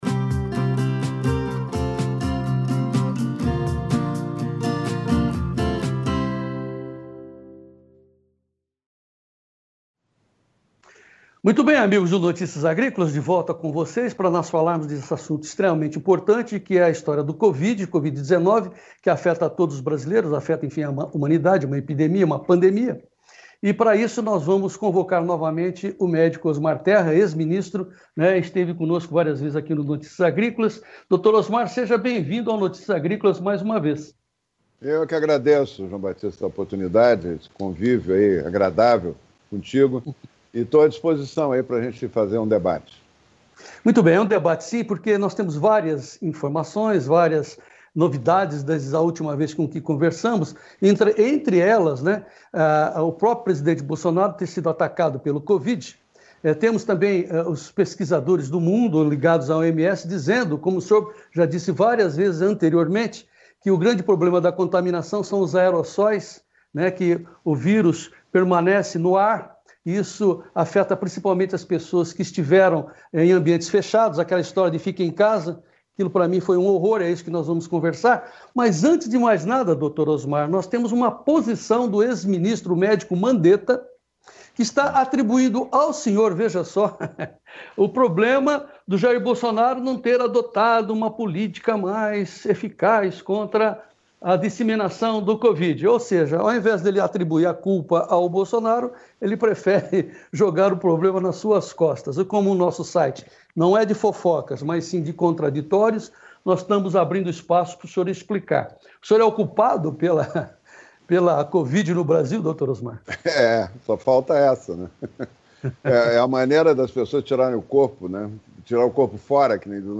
Entrevista com Osmar Terra - Deputado Federal - MDB/RS sobre o Coranavírus